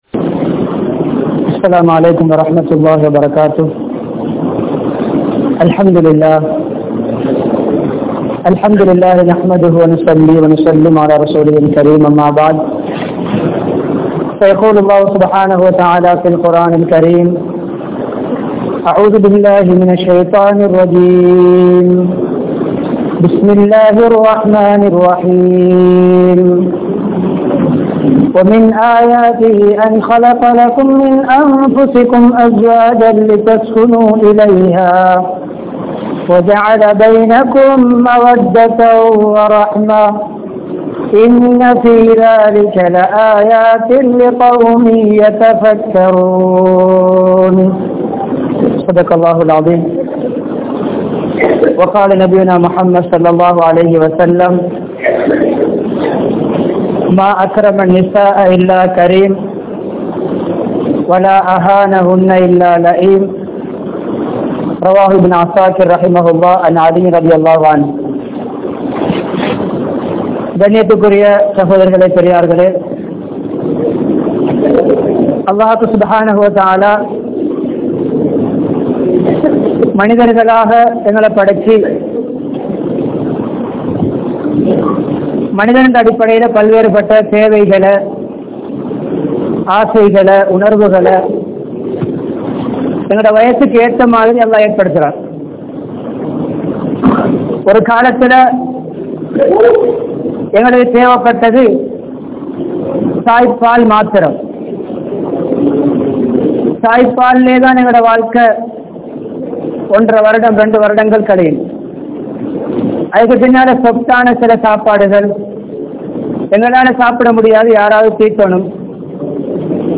Manaiviudan Anpaaha Palahungal (மனைவியுடன் அன்பாக பழகுங்கள்) | Audio Bayans | All Ceylon Muslim Youth Community | Addalaichenai